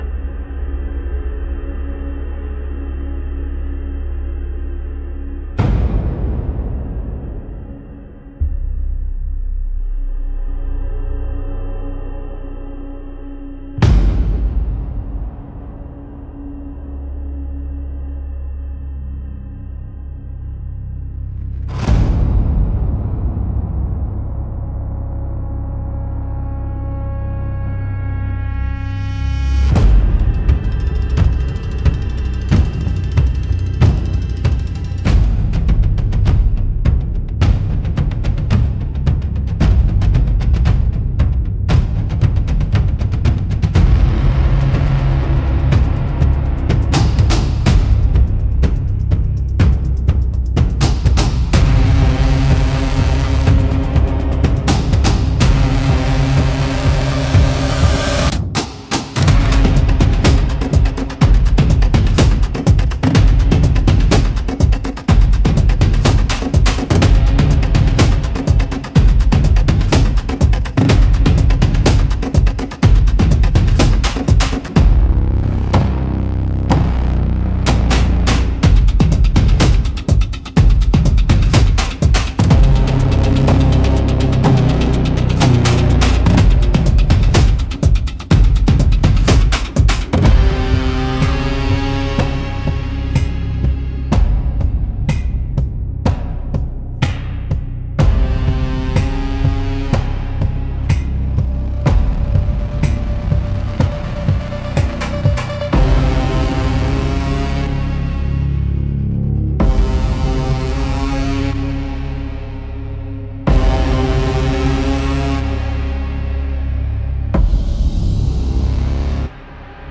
Instrumental - Heart of the Codebreaker -2.25